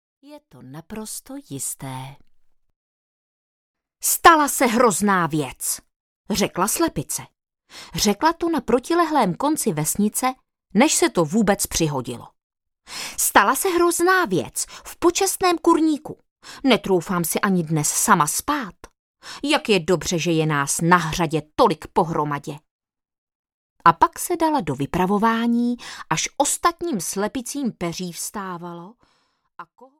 Ukázka z knihy
je-to-naprosto-jiste-audiokniha